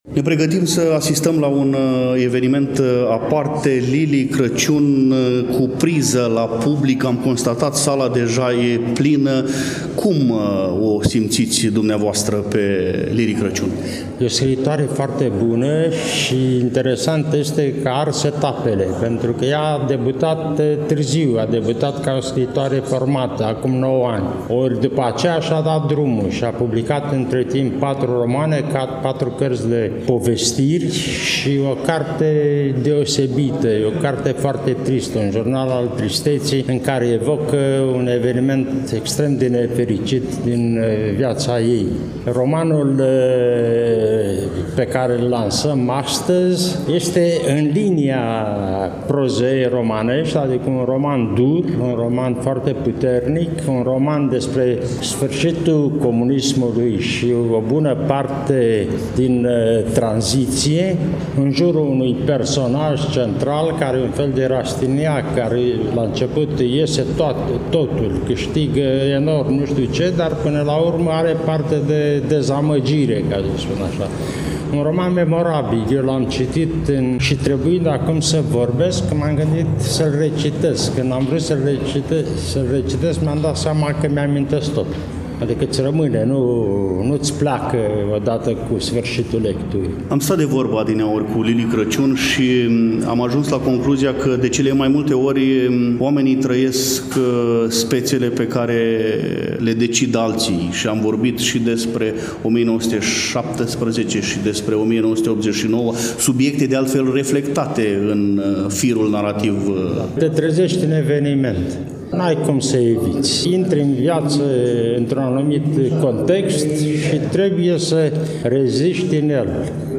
Evenimentul s-a desfășurat la Iași, nu demult, în librăria cafenea „Orest Tafrali” din incinta Universității „Alexandru Ioan Cuza”.